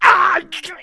injurH5.wav